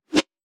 weapon_bullet_flyby_11.wav